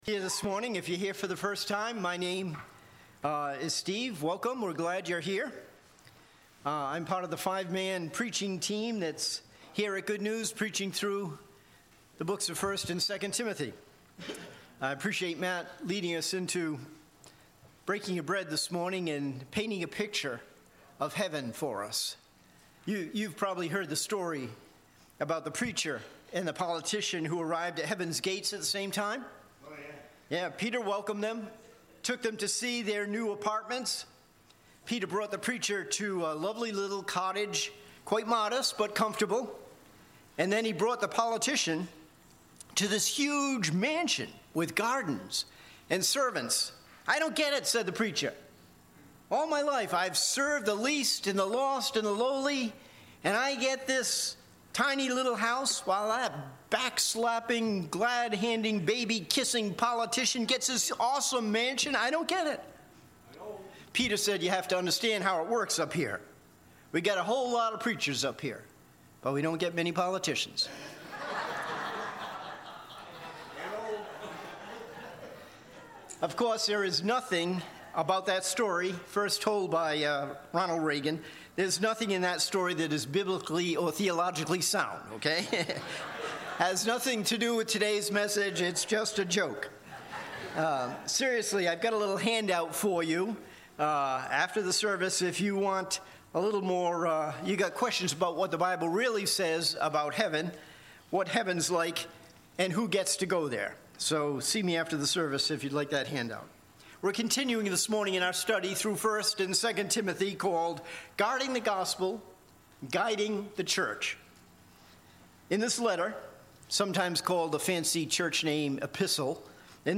Series: 1 & 2 Timothy - Guarding the Gospel - Guiding the Church Service Type: Celebration & Growth